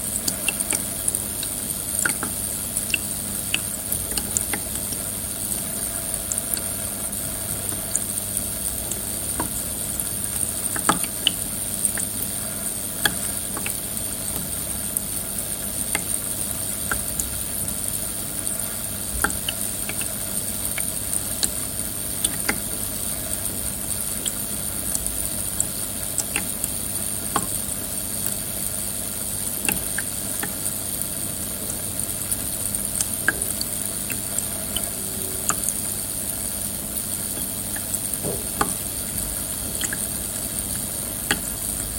Звуки увлажнителя воздуха: как работает ультразвуковой увлажнитель